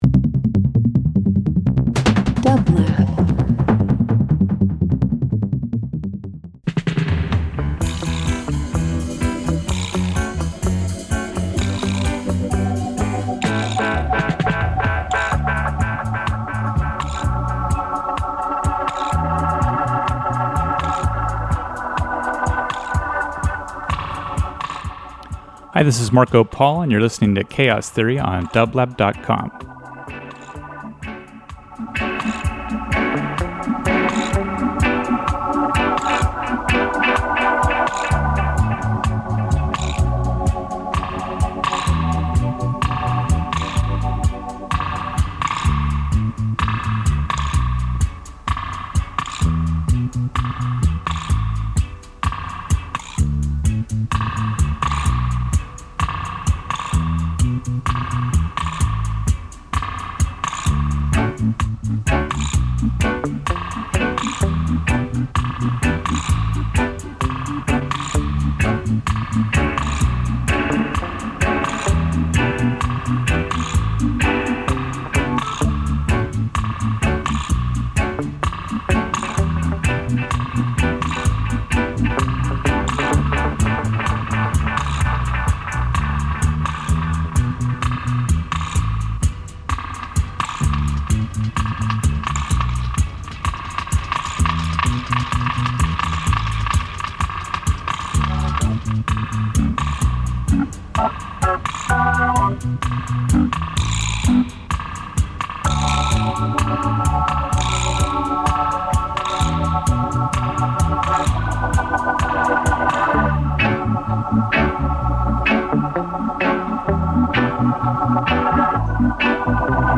all genres. deeper tracks. no rules.
Alternative Jazz Rock